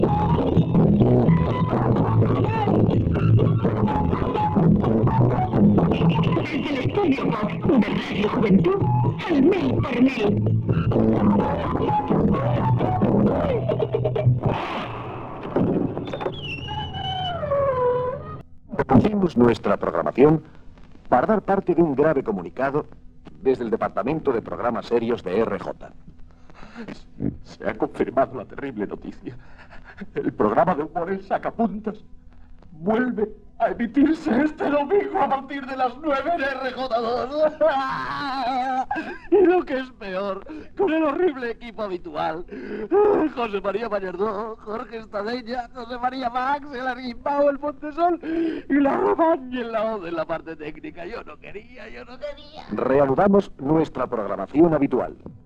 Indicatiu del programa i anunci de l'emissió de "El sacapuntas", el diumenge.